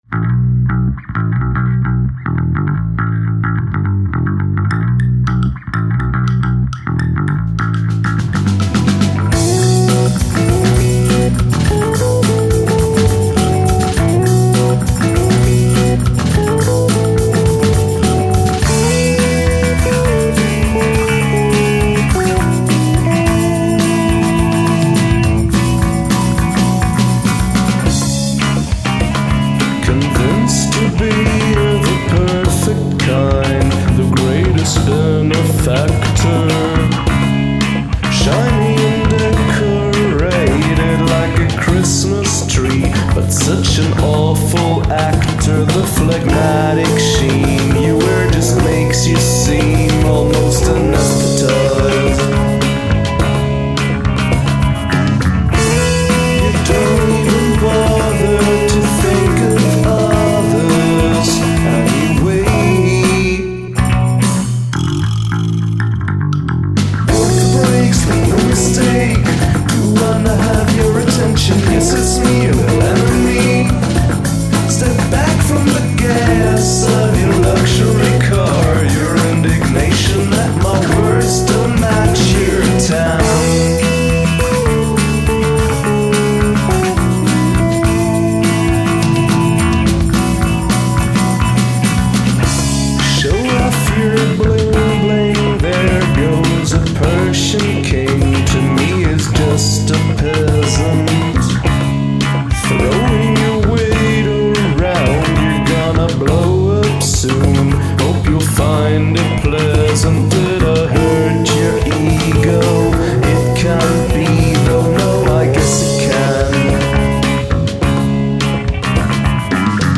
Diss Track
The instrumentation buildup is fantastic.